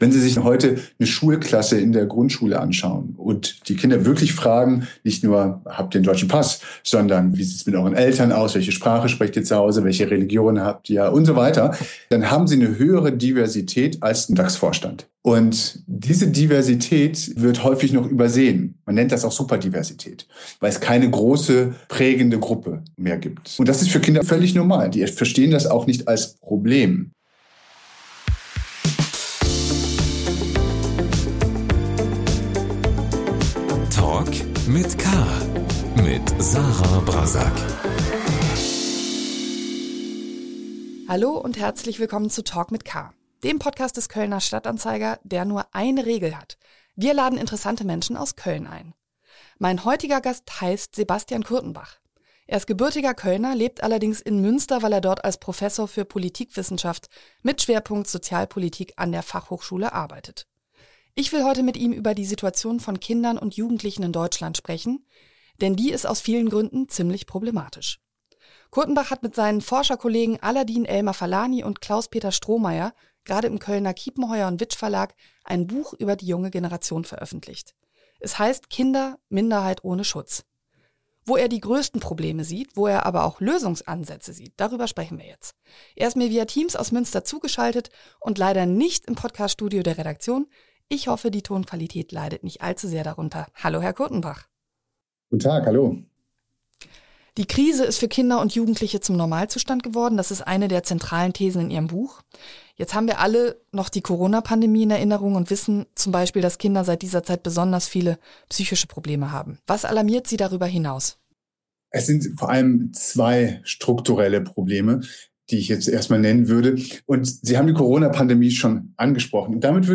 Der Politikwissenschaftler im Gespräch 42:22